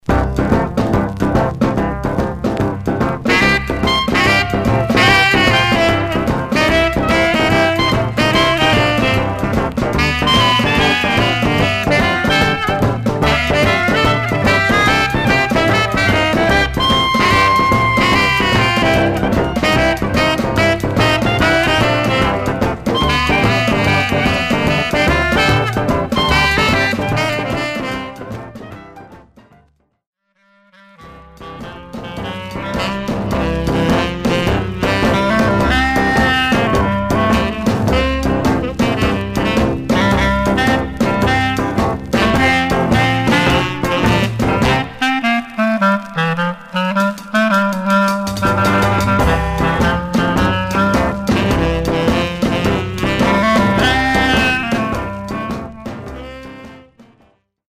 Some surface noise/wear
Mono
R&B Instrumental